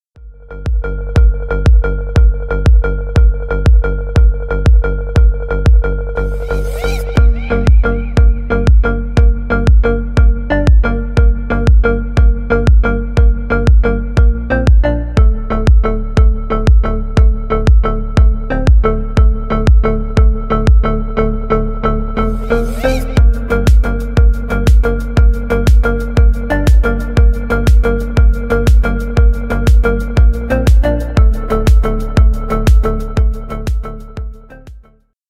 Stereo
Танцевальные зарубежные клубные без слов